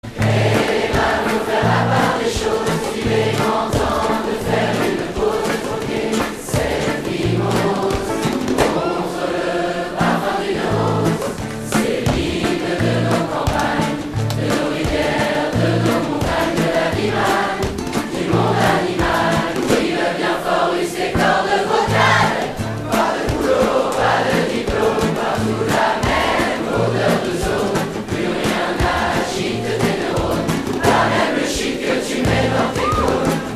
reggae acoustic